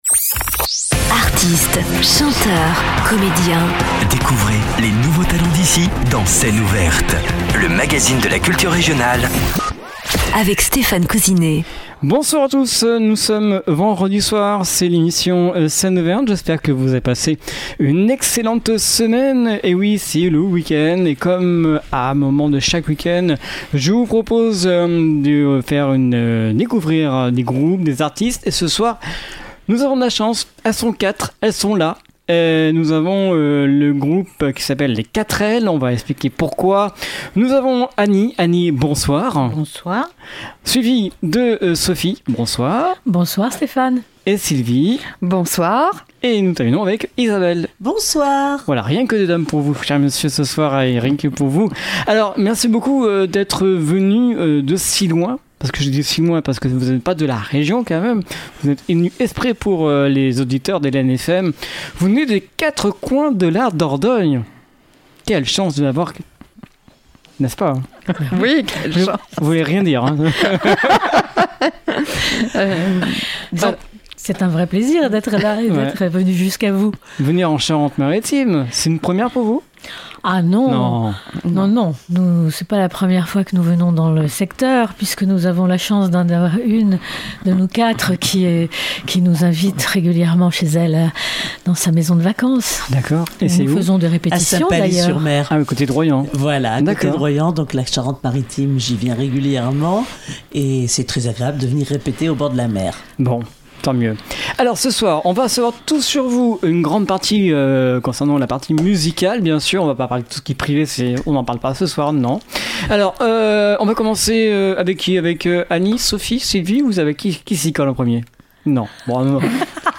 Scène ouverte avec le quartet Les Quatr’elles
musique swing, rêverie et poésie.